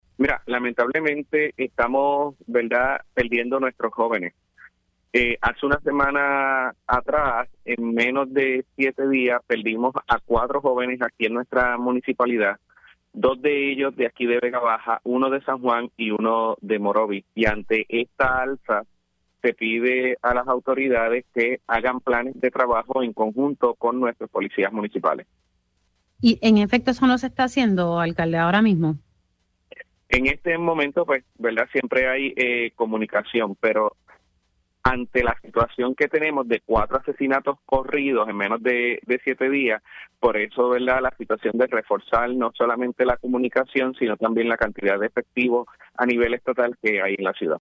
114-MARCOS-CRUZ-ALC-VEGA-BAJA-PIDE-AUMENTO-DE-AGENTES-ESTATALES-EN-SU-MUNICIPIO.mp3